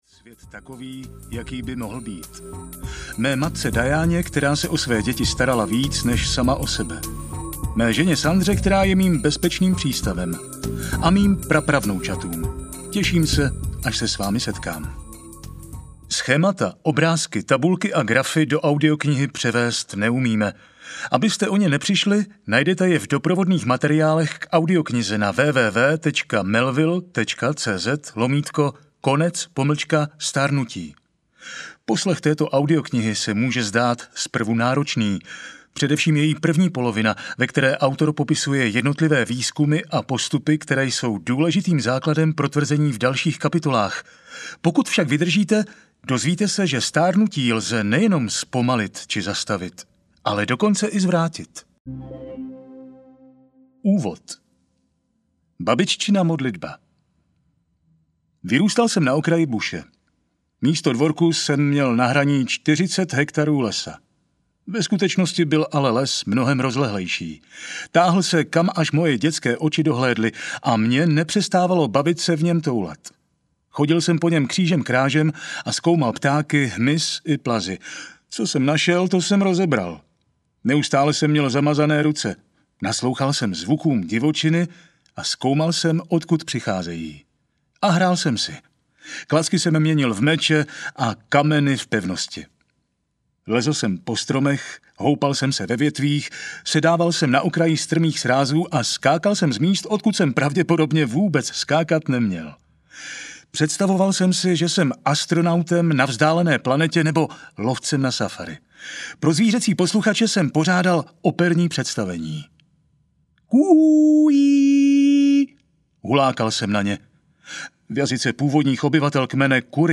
Konec stárnutí audiokniha
Ukázka z knihy